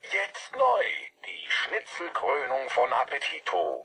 Werbung Prosodie: accent tonique und intonation descendante en fin de phrases déclaratives